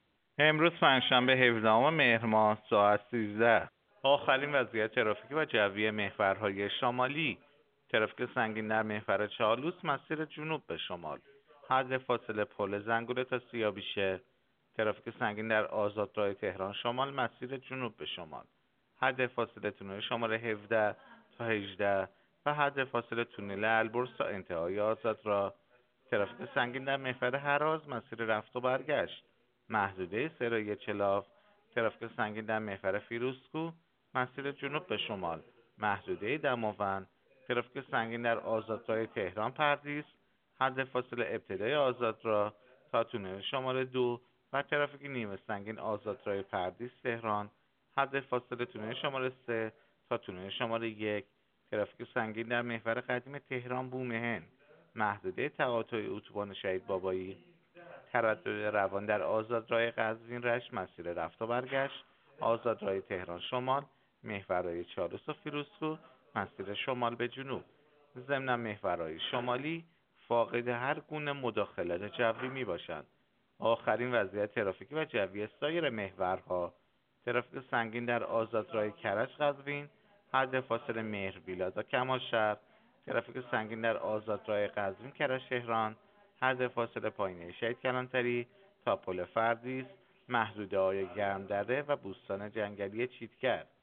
گزارش رادیو اینترنتی از آخرین وضعیت ترافیکی جاده‌ها ساعت ۱۳ هفدهم مهر؛